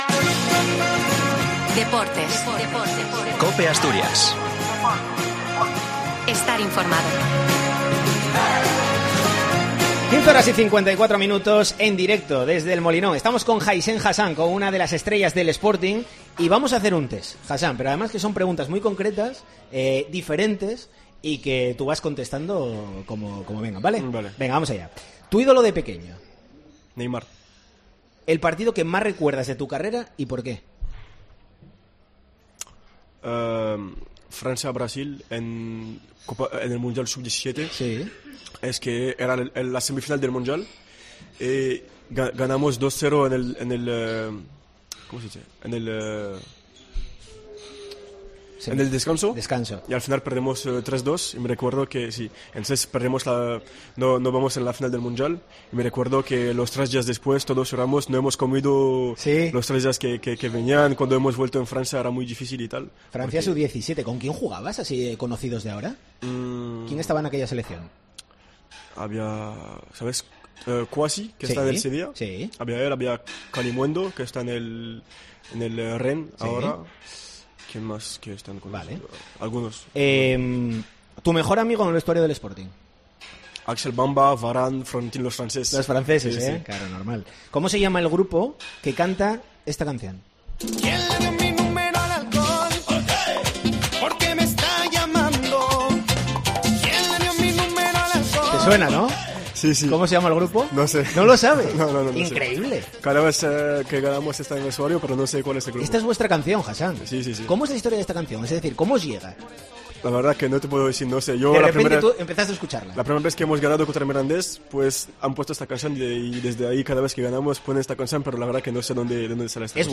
Entrevista a Hassan en COPE, desde La Cañada Real Molinón.